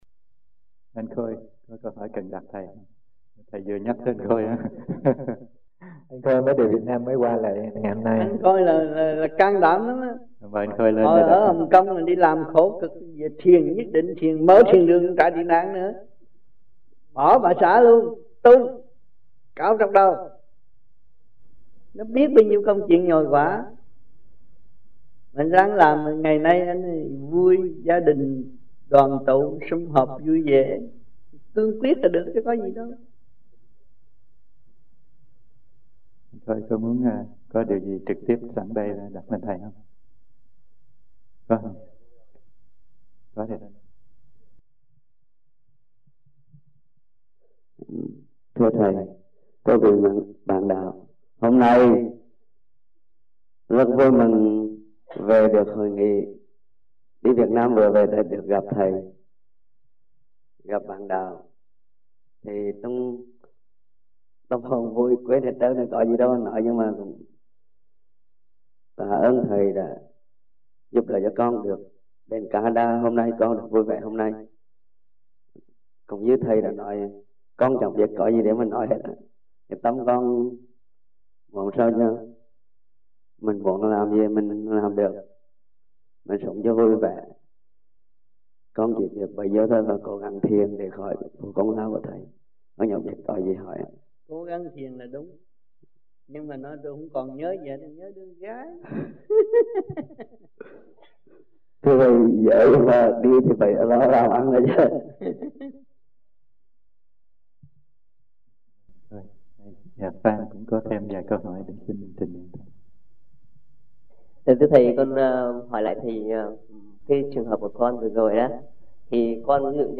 1994 Khóa Học Tại Vancouver